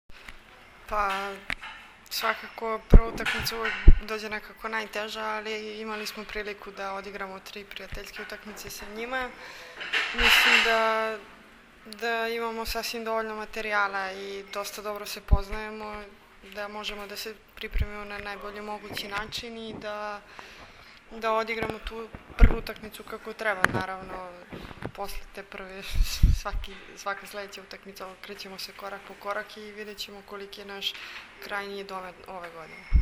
IZJAVA SUZANE ĆEBIĆ